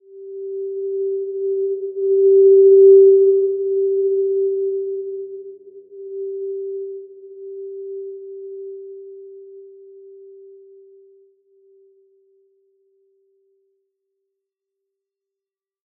Simple-Glow-G4-p.wav